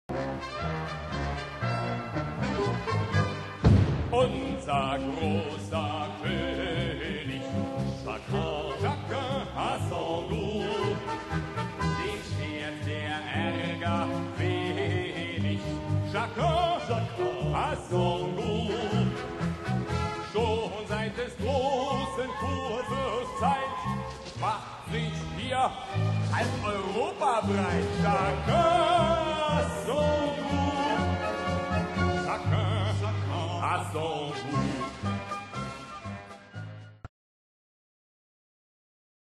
Solo-Drummer und Erzähler